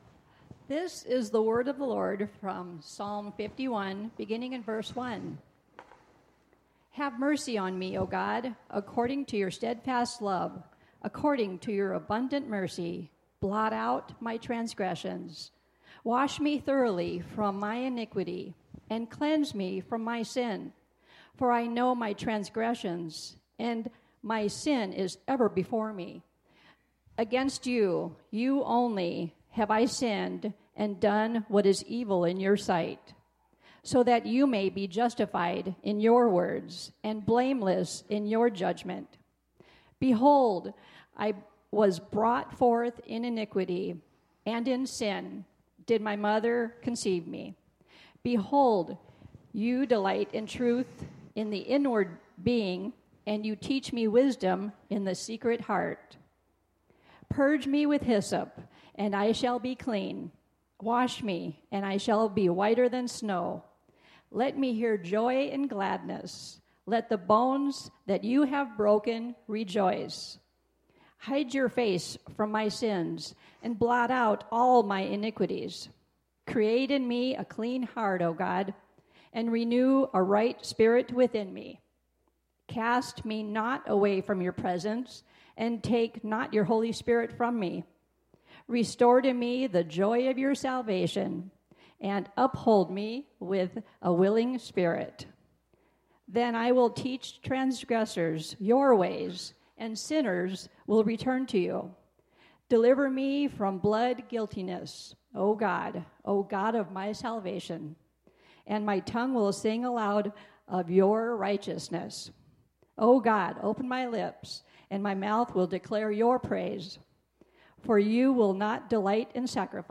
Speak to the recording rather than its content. Psalm 51 Service Type: Sunday Morning Bible Text